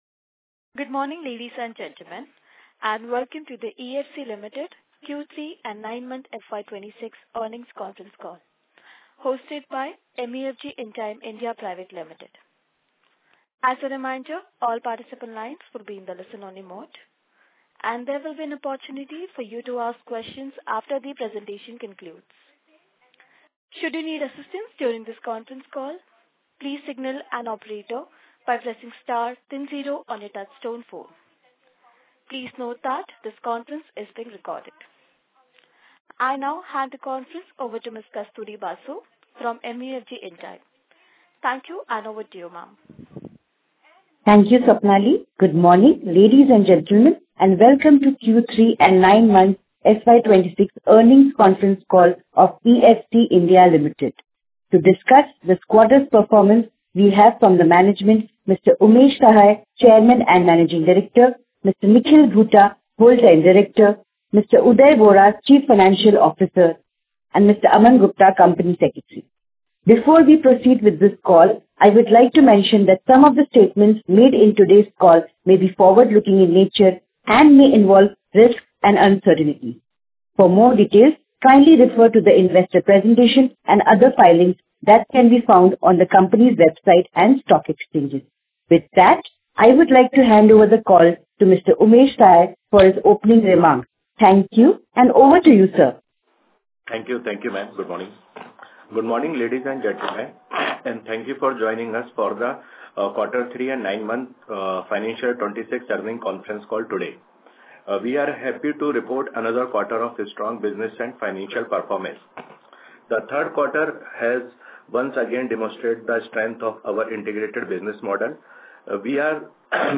EFC (I) Limited Makes Q3FY26 Earnings Conference Call Recording Available Online